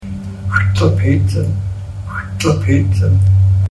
pronunciation of xʷƛ̓əpicən
xʷƛ̓əpicən pronunciation.mp3